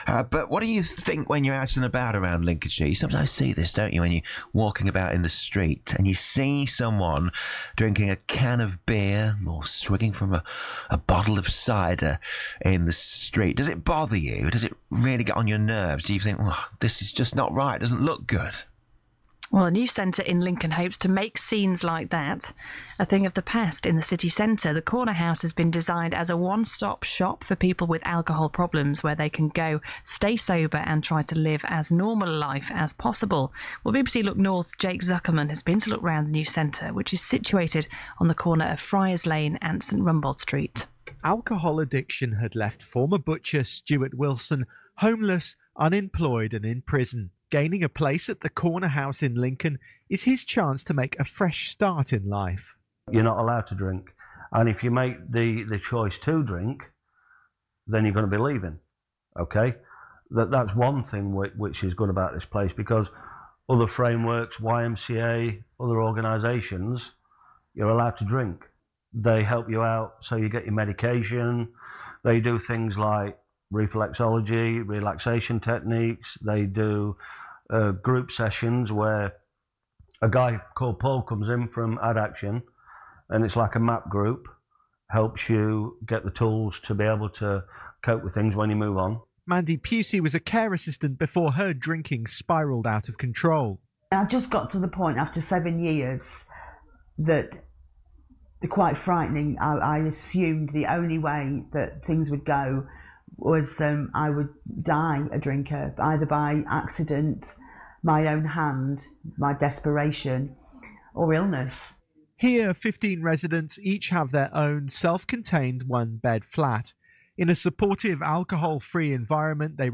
The Corner House is Framework's newest accommodation development - designed to support people who are homeless as a result of chronic alcohol problems. It was featured on BBC Radio Nottingham on Wednesday, February 18 as we hosted an open day for members of the public.